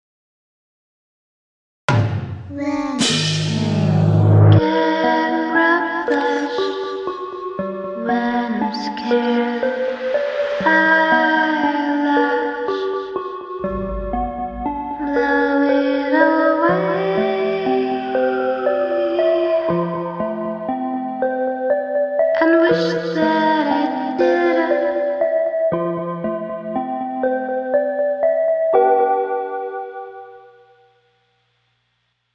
I love her vocals, very fluid 😩😩💞